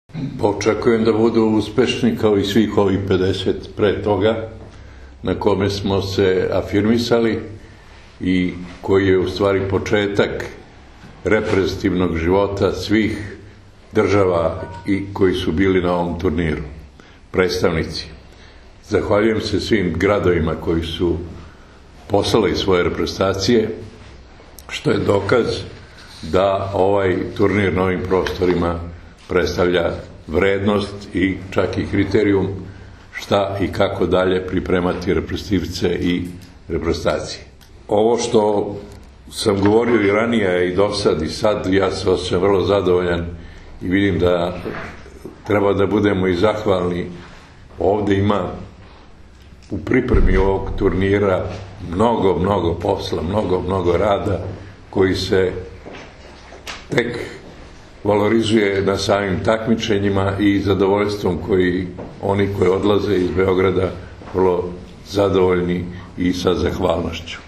U beogradskom hotelu “Belgrade City” danas je održana konferencija za novinare povodom 51. Međunarodnog “Majskog turnira” 2016, koji će se odigrati od 30. aprila – 2. maja.
IZJAVA